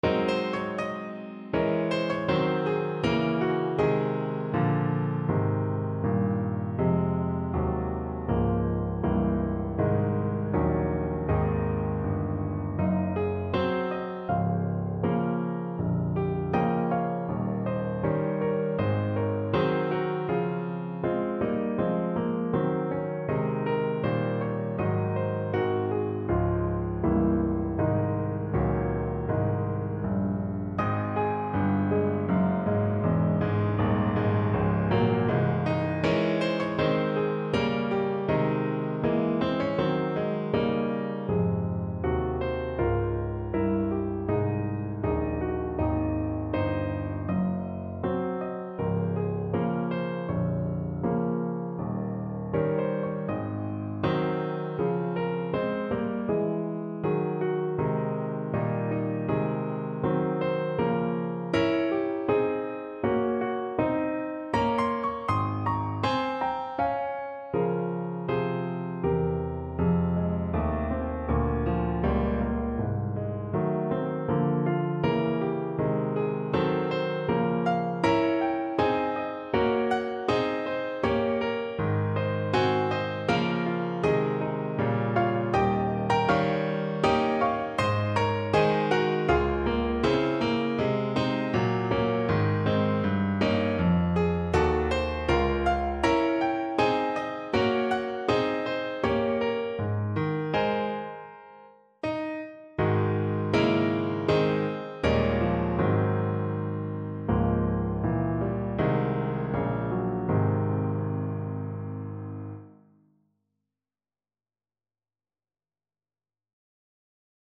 Alto Saxophone
Traditional Music of unknown author.
Ab major (Sounding Pitch) F major (Alto Saxophone in Eb) (View more Ab major Music for Saxophone )
Andante
4/4 (View more 4/4 Music)
verdant_braes_ASAX_kar1.mp3